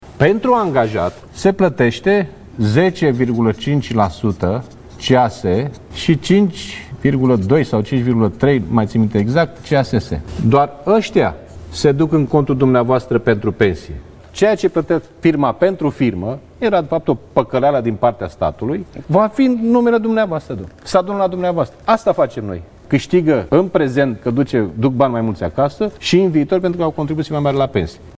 Astfel, liderul PSD a susţinut duminică, într-un interviu la Antena 3, că în acest moment doar contribuţia individuală la bugetul asigurărilor sociale ar avea un imact asupra pensiei unui angajat, nu şi sumele pe care le plăteşte angajatorul.
28aug-18-Liviu-Dragnea-voce-contributii.mp3